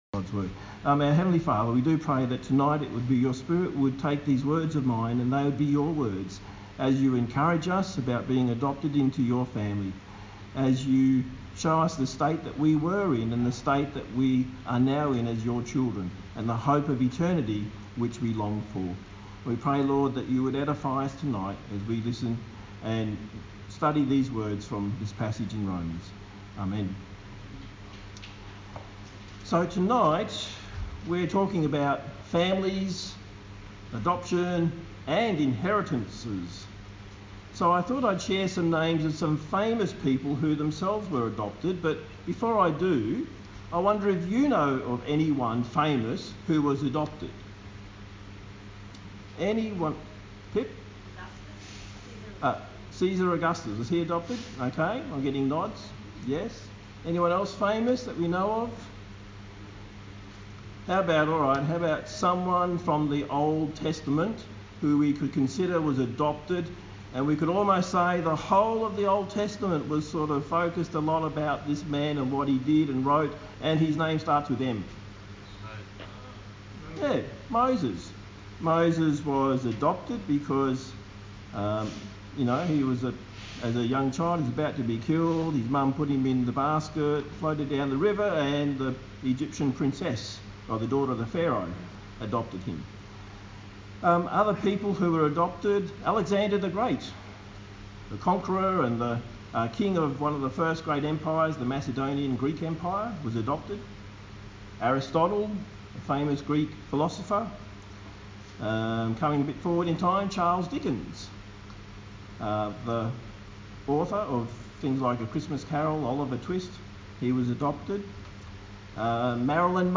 A sermon on the book of Romans